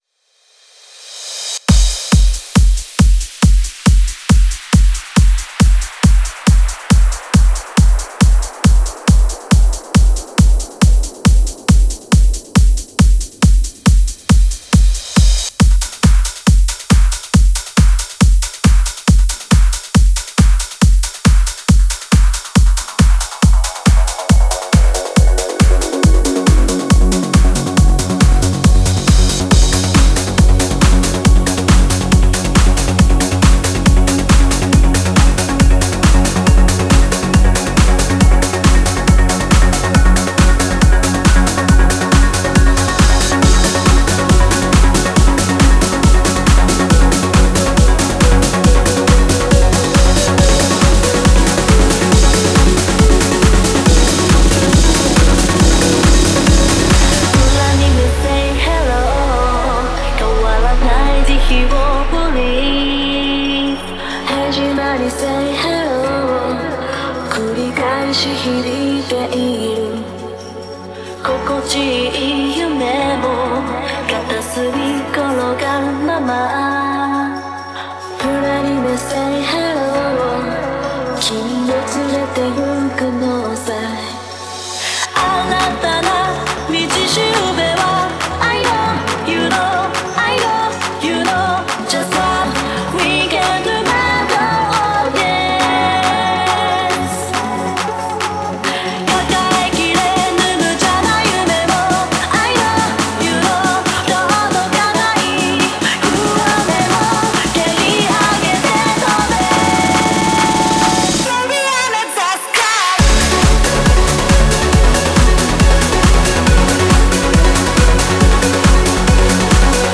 Genre: Trance
BPM : 138
Release Type: Bootleg